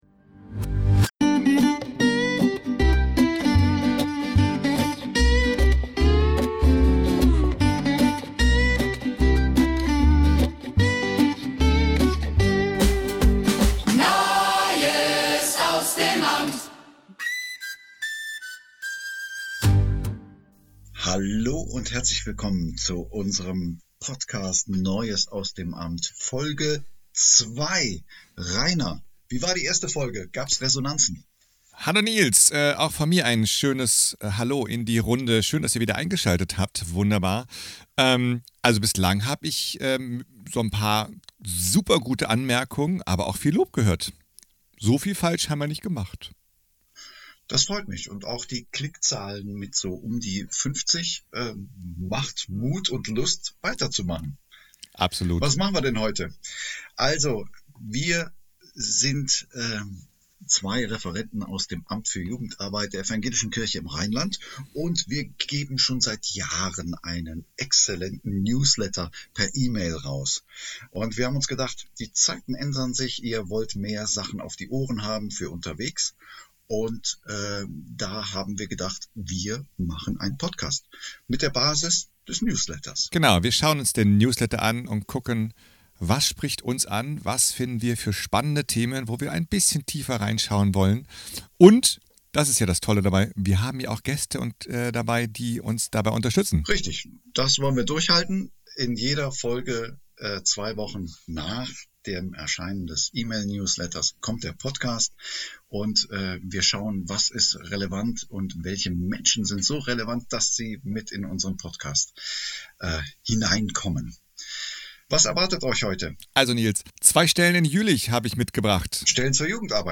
Themen: neue Stellenangebote, kreative Aktivitäten wie Skaten, Bouldern oder Eislaufen in kirchlichen Räumen, KI‑Weiterbildungen sowie kommende Veranstaltungen wie Forschungsfreitag und Jugendkongress AC 26. Interviews & Praxis-Einblicke Zwei zentrale Themen werden vertieft: Crashkurs für Neue in der Jugendarbeit: Strukturwissen, Vernetzung, Rollenklärung und Austausch.